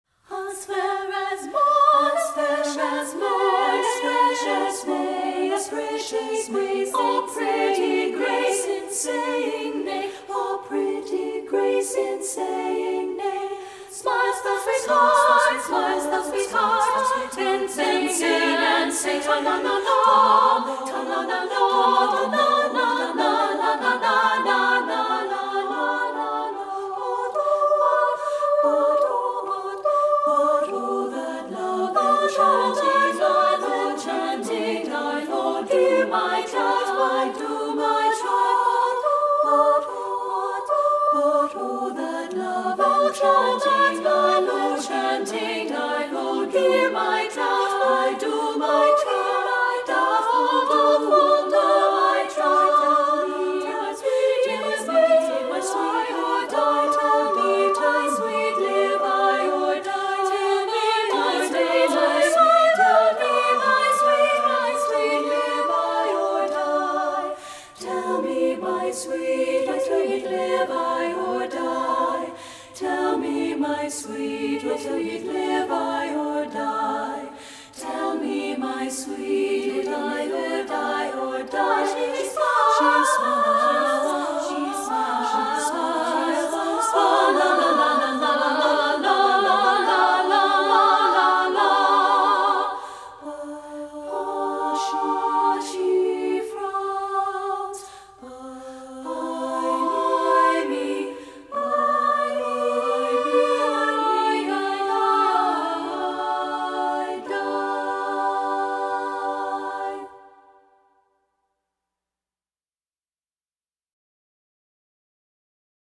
• Soprano 1
• Soprano 2
• Alto
Studio Recording
Ensemble: Treble Chorus
Key: G major
Accompanied: A cappella